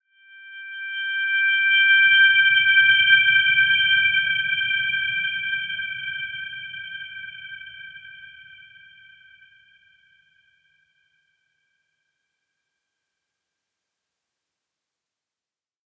Dreamy-Fifths-G6-f.wav